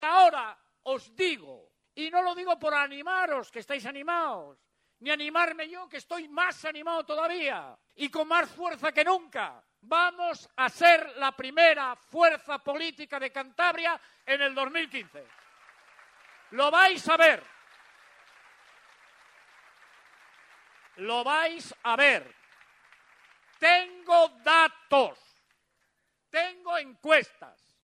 Revilla al inicio de la comida de Navidad celebrada hoy por el PRC
Revilla pronunci? su discurso ante m?s de 500 personas